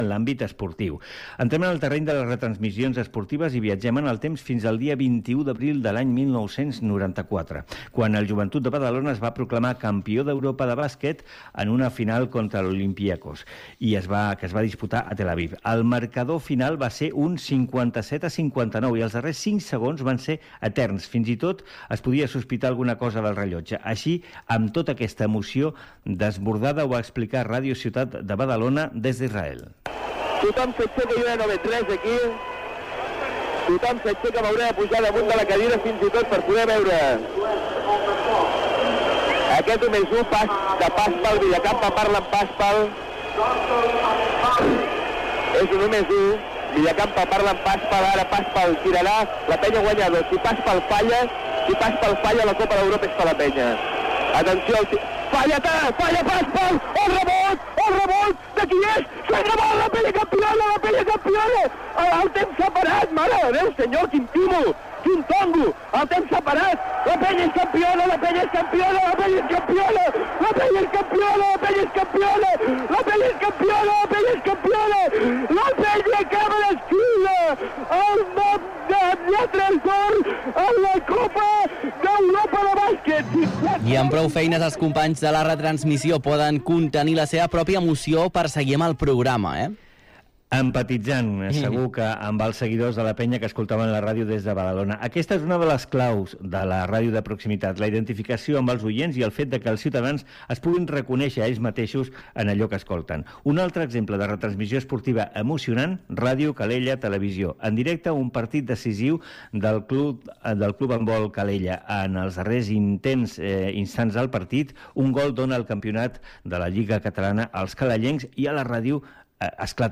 Entreteniment
Presentador/a
FM